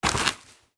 Dirt footsteps 20.wav